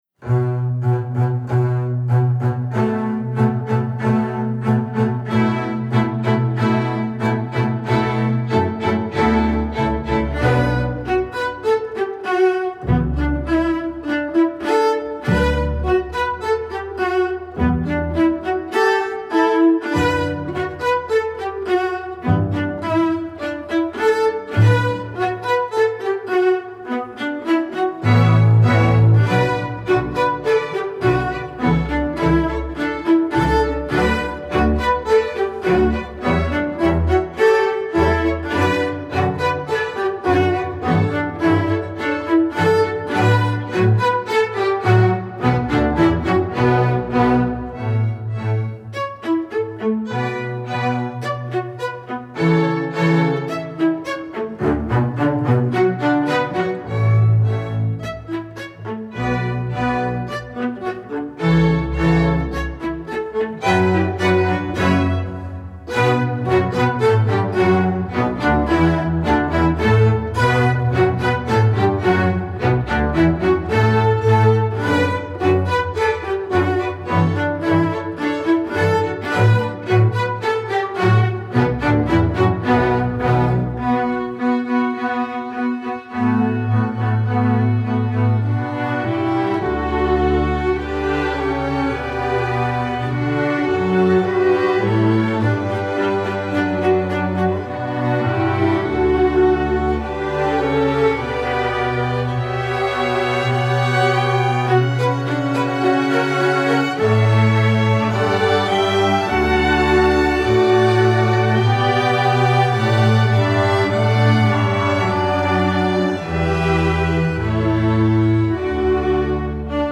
Voicing: String Orc